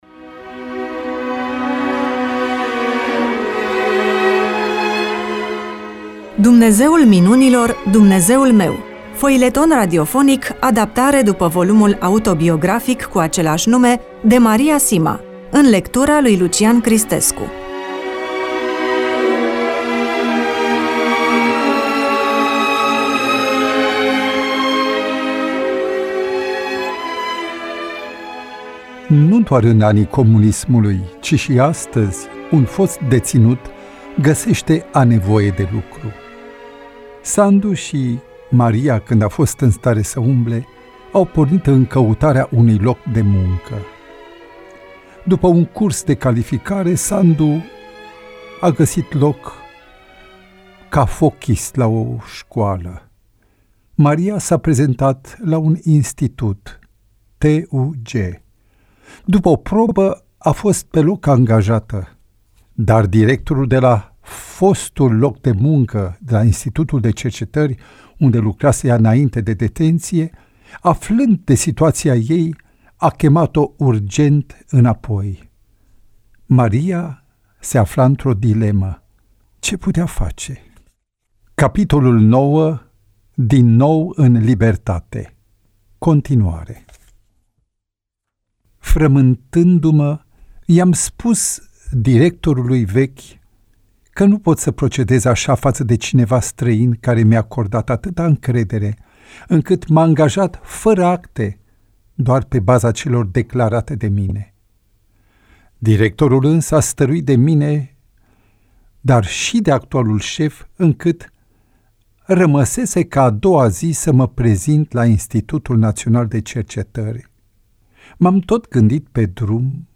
EMISIUNEA: Roman foileton DATA INREGISTRARII: 03.04.2026 VIZUALIZARI: 20